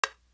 Living The Life Rimshot.wav